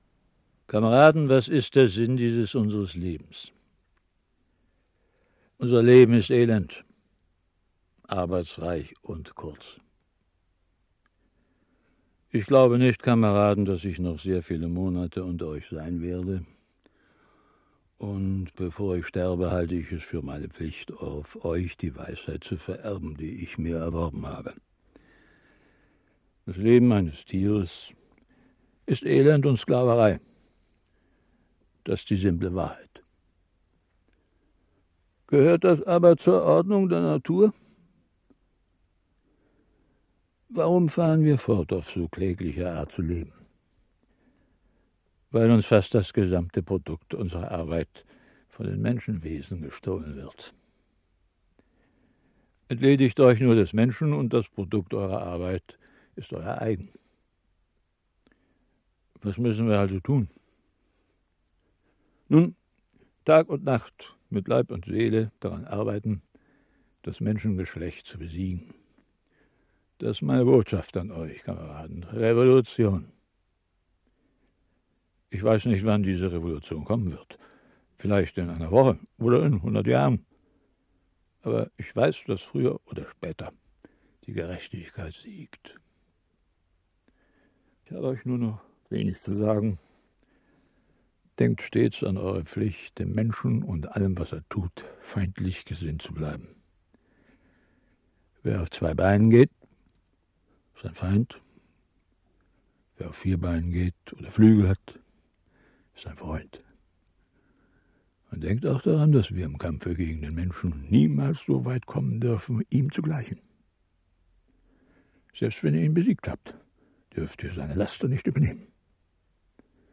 Hörspiel
Otto Sander, Bernhard Minetti (Sprecher)
Große Weltliteratur – brillant als Hörspiel umgesetzt mit Otto Sander, Bernhard Minetti u.v.a.